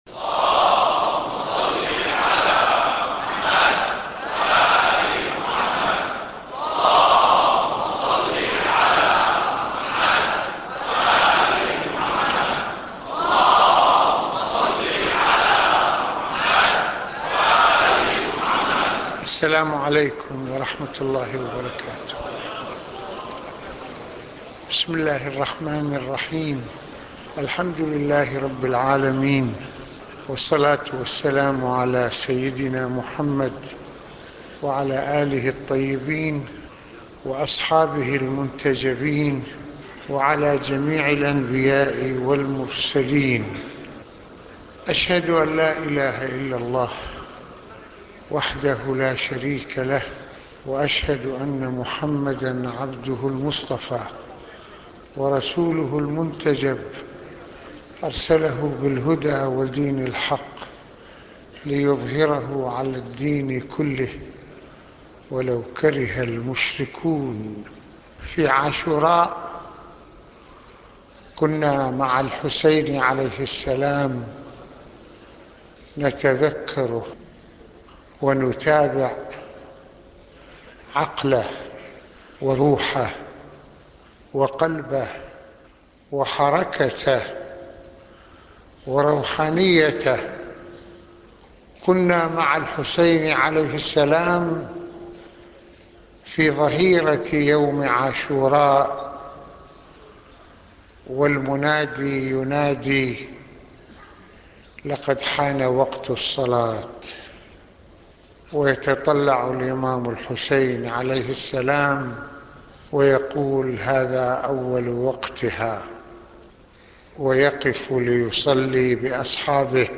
- المناسبة : خطبة الجمعة المكان : مسجد الإمامين الحسنين(ع) المدة : 19د | 20ث المواضيع : على خطِّ الحسين ونهجه ومبادئه - تأكيد صدق الانتماء إلى الرسالة بالعمل - عقيدة وموقف - حركة الحسين(ع) حركة عدل - هل نحن على نهج الحسين(ع)؟.